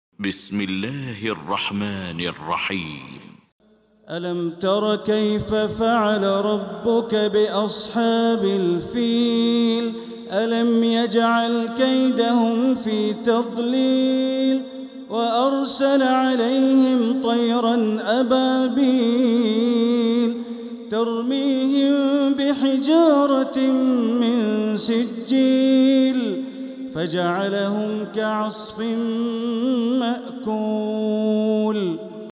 المكان: المسجد الحرام الشيخ: معالي الشيخ أ.د. بندر بليلة معالي الشيخ أ.د. بندر بليلة الفيل